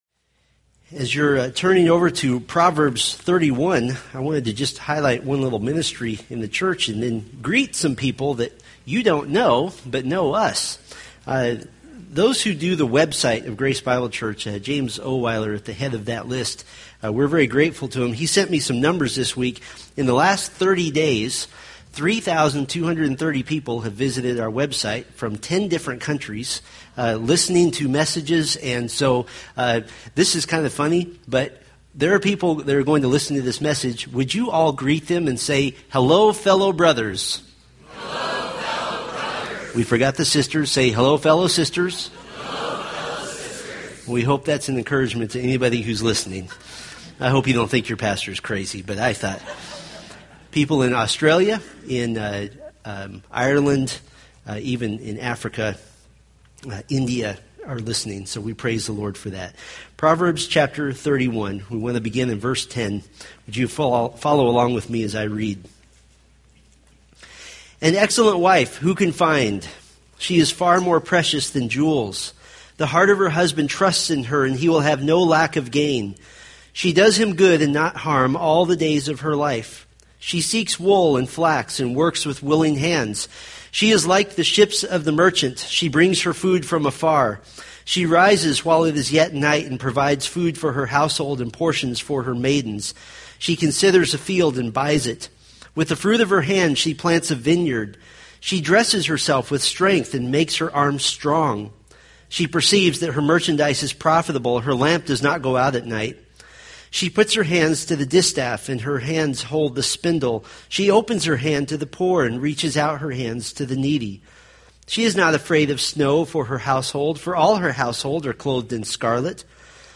From the Family Wisdom from the Wisdom Family sermon series.
Sermon Details